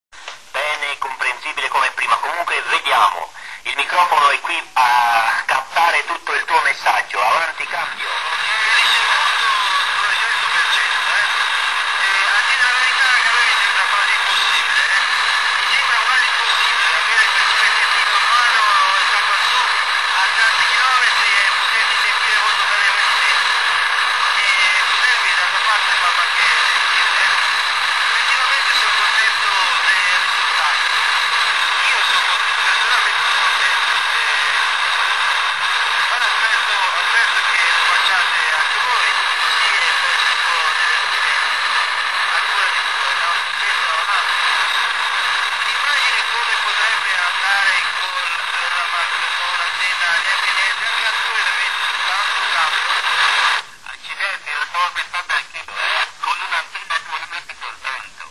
Provai due antenne accordate alla meglio, un dipolo ed uno stilo.